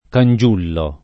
[ kan J2 llo ]